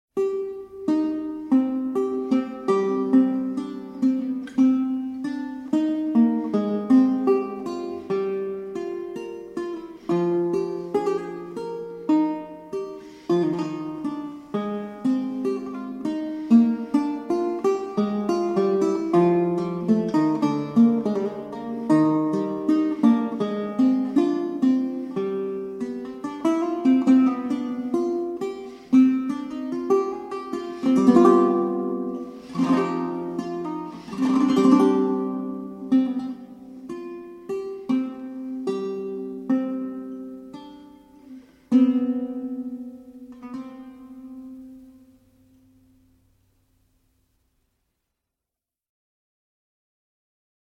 performed on baroque guitar.